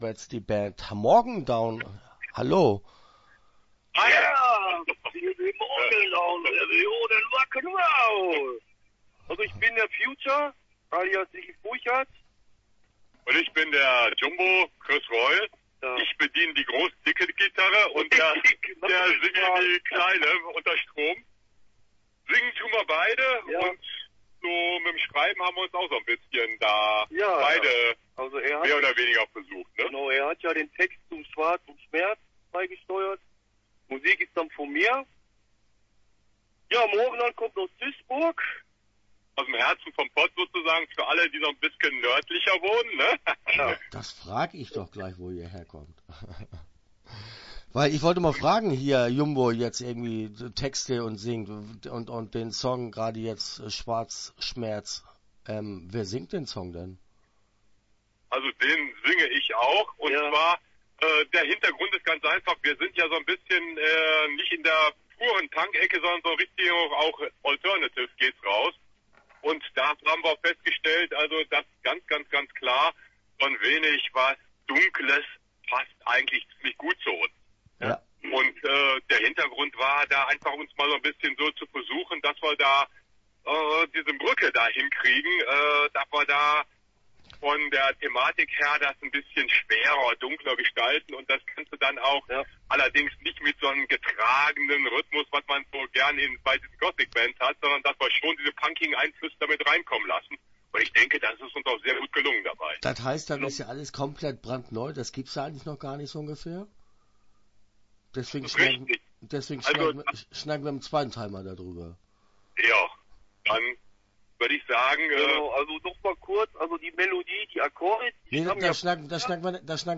Start » Interviews » MORGENDOWN